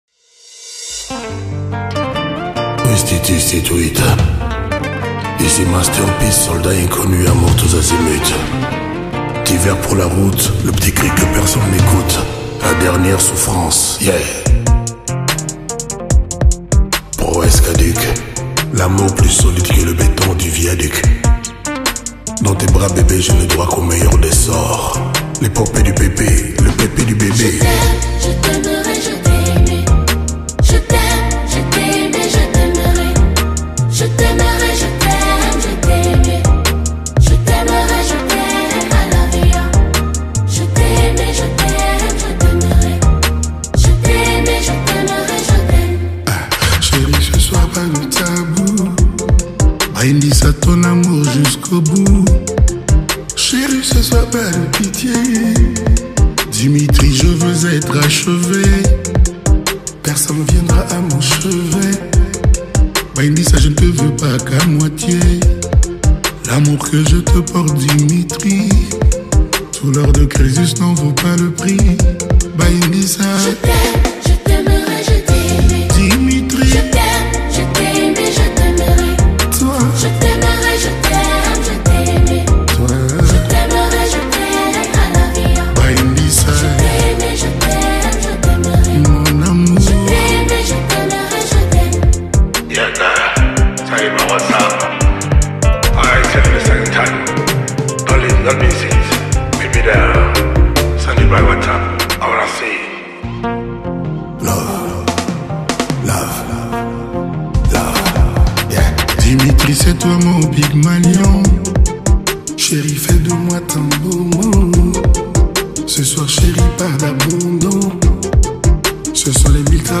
sur un rythme très entraînant